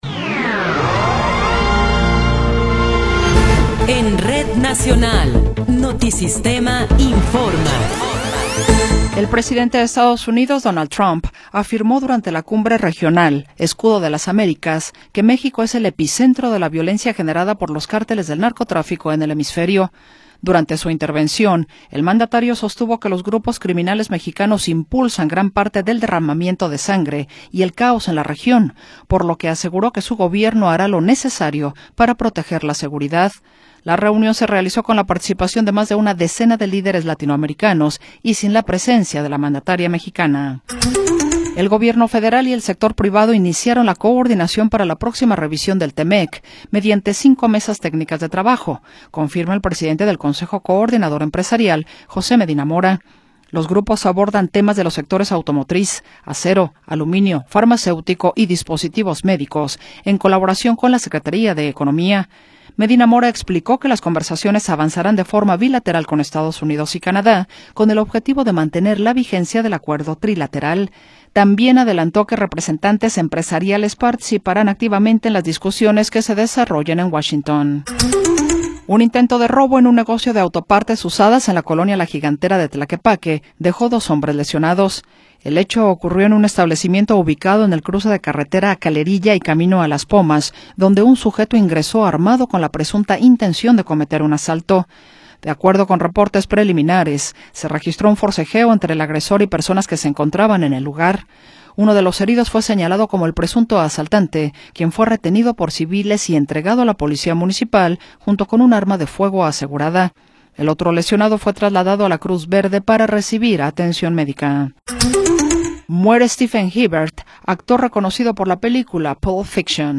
Noticiero 11 hrs. – 7 de Marzo de 2026